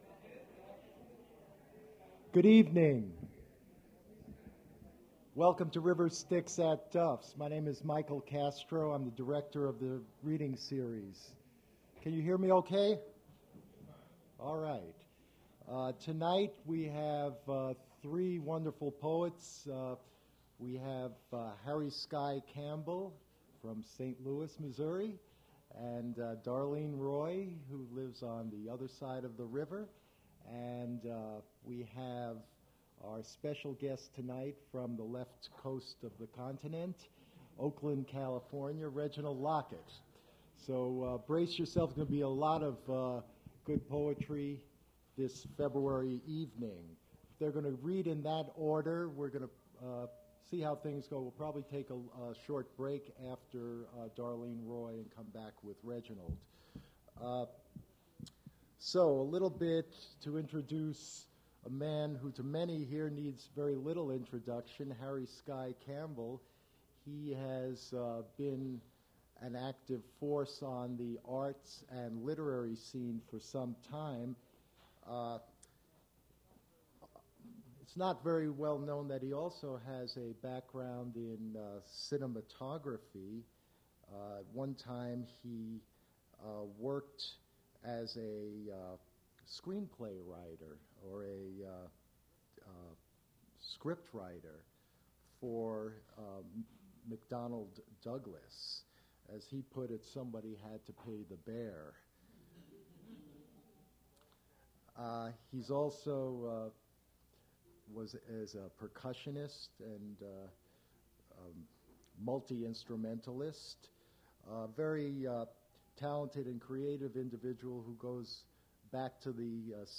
Poetry reading
mp3 edited access file was created from unedited access file which was sourced from preservation WAV file that was generated from original audio cassette. Language English Identifier CASS.785 Series River Styx at Duff's River Styx Archive (MSS127), 1973-2001 Note Removed closing remarks from event organizer.